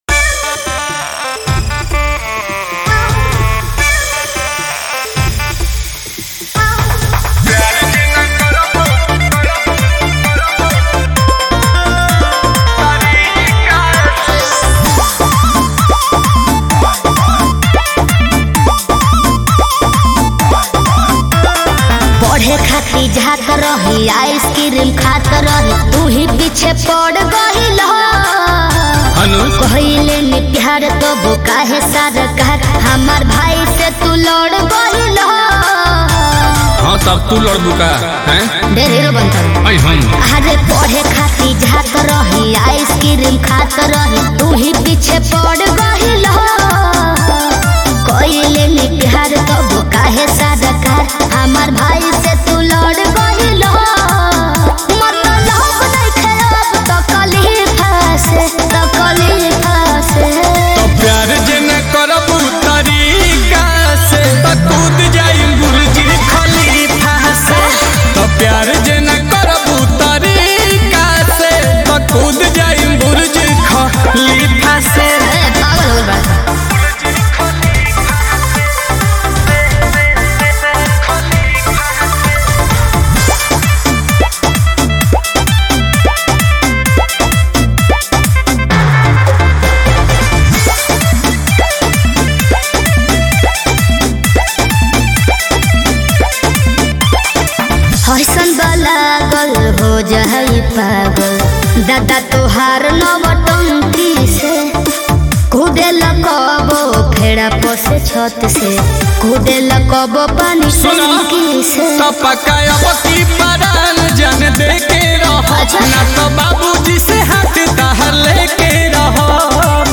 Bhojpuri Mp3 Songs